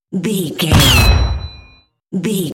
Dramatic hit metal electricity
Sound Effects
heavy
intense
dark
aggressive